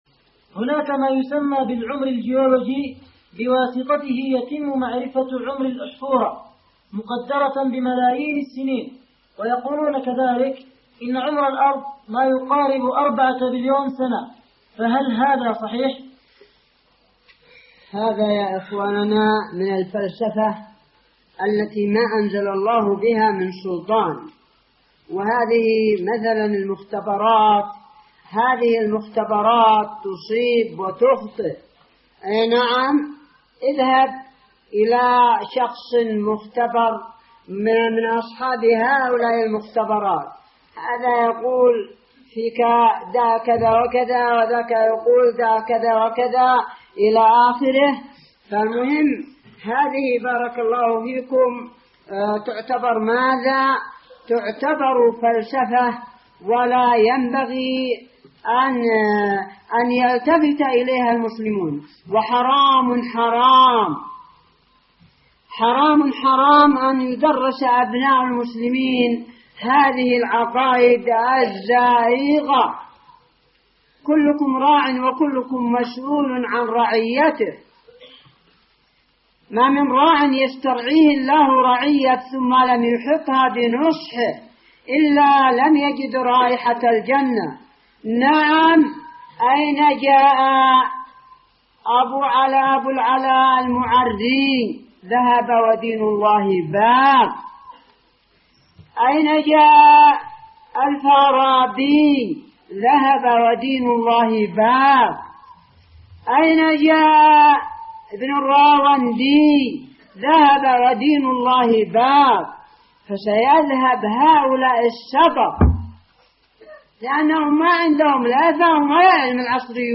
-------------- من شريط : ( الأسئلة الجولوجية من الجامعة اليمنية )